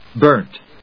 /bˈɚːnt(米国英語), bˈəːnt(英国英語)/